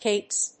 /keps(米国英語), keɪps(英国英語)/